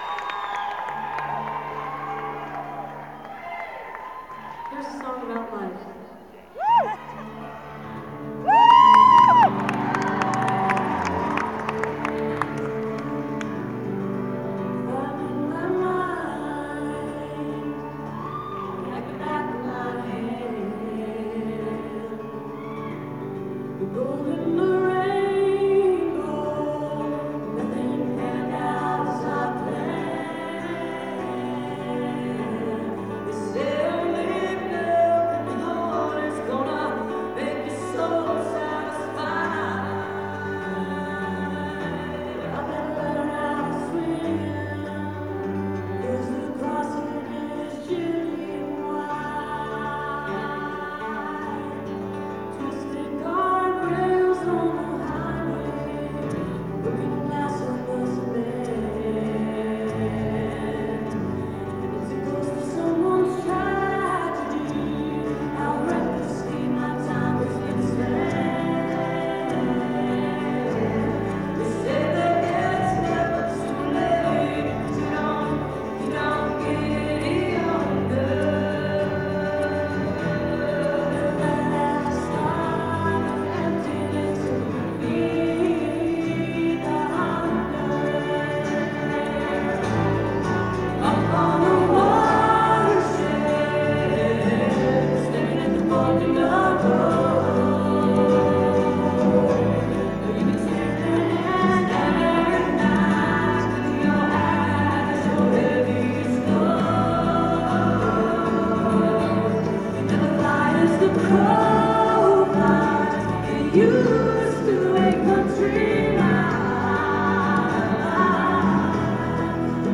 acoustic duo